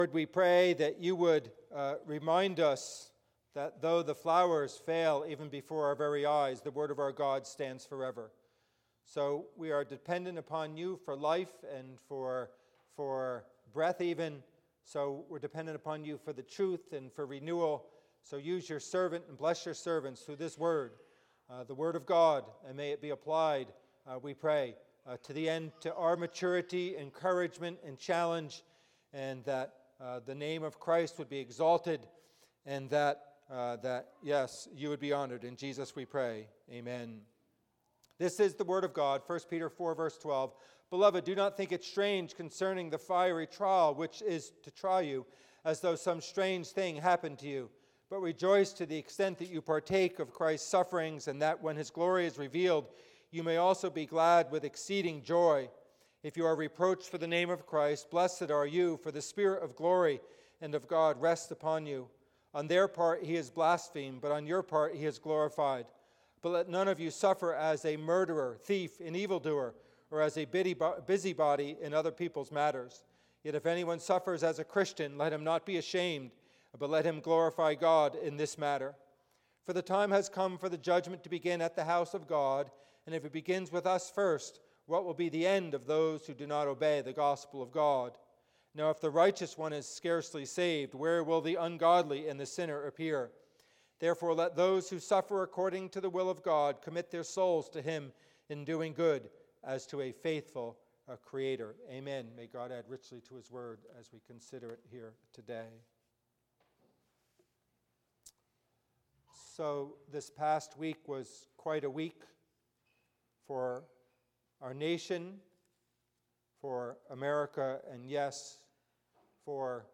Passage: 1 Peter 4:12-19 Service Type: Worship Service